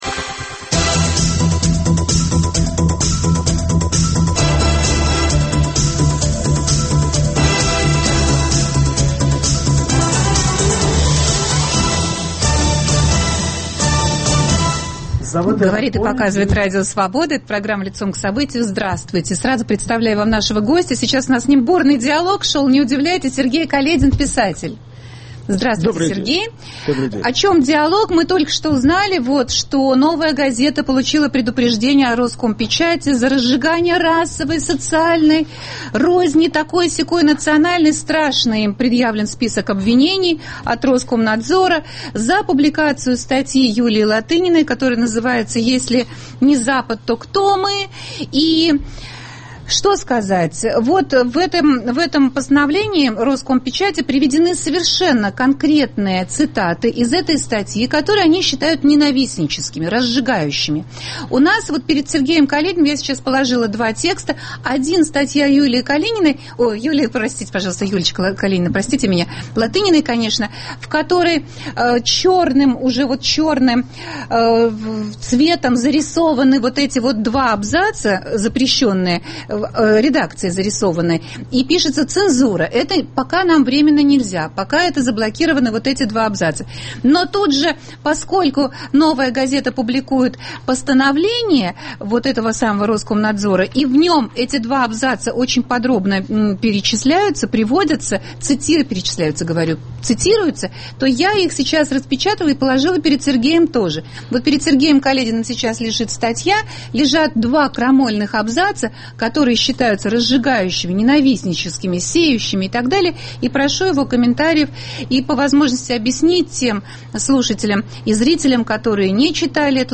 Говорим с писателем Сергеем Калединым о последних новостях, связанных с российско-украинскими отношениями.